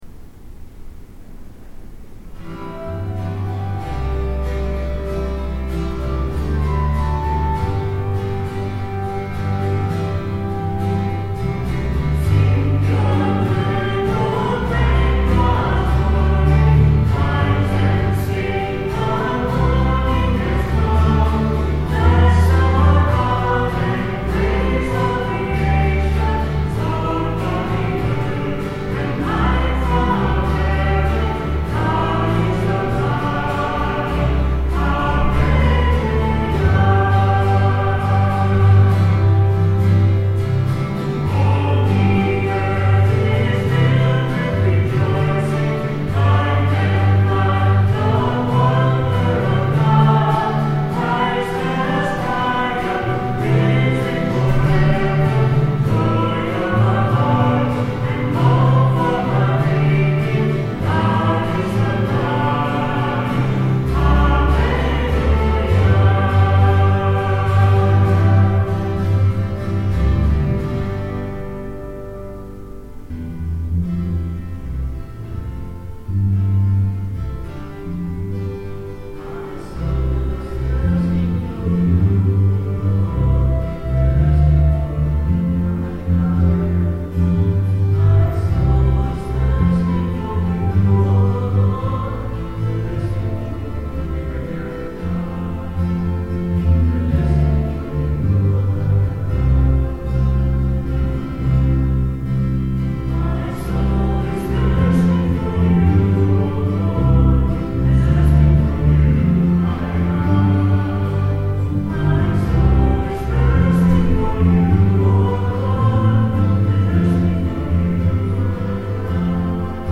10/15/09 10:30 Group Practice - BK1030
Note that all conversations have been removed from this sequence. Also note that the recorder was placed behind the last pew, near the sacristy, close to the center aisle. This was recorded from the perspective of someone sitting in the congregation.